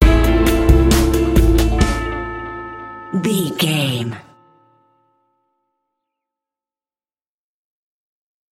Aeolian/Minor
drums
electric guitar
bass guitar